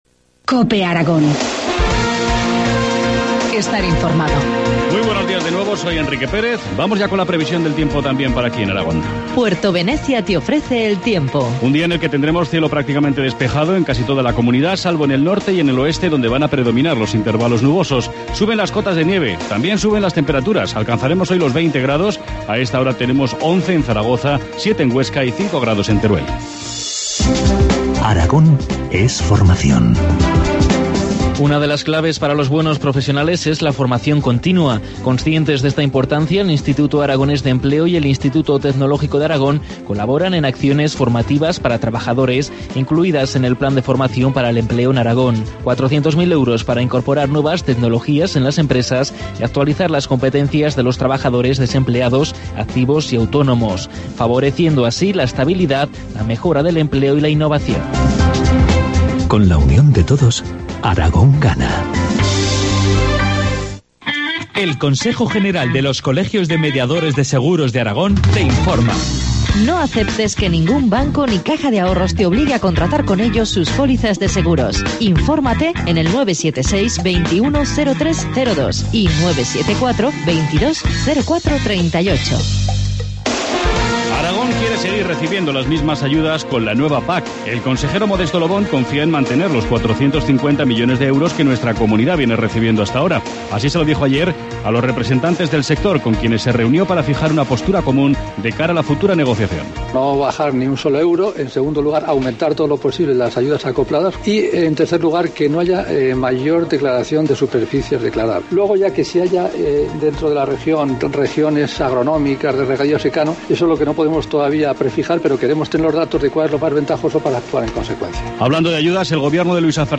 Informativo matinal, martes 9 de abril, 8.25 horas